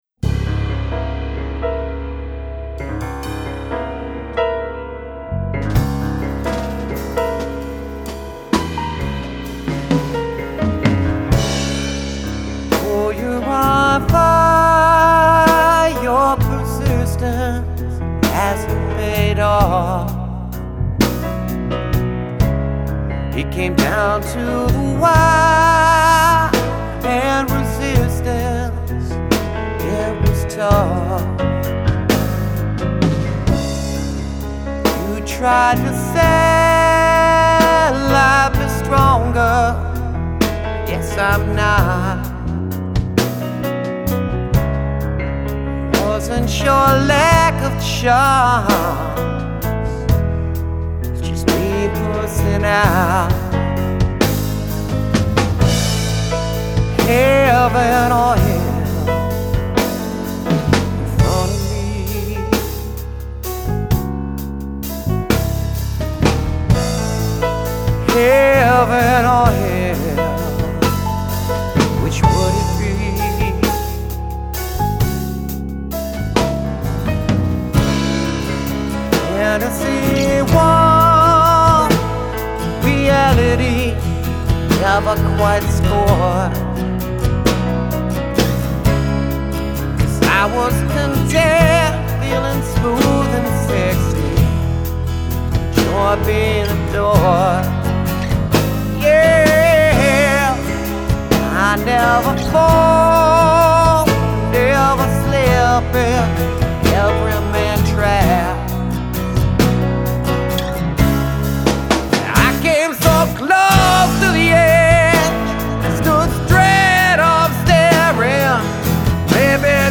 Genre: Alternative power pop.